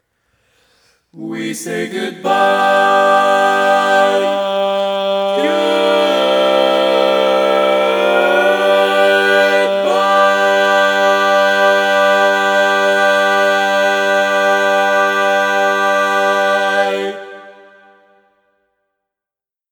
How many parts: 4
Type: Barbershop
All Parts mix:
Learning tracks sung by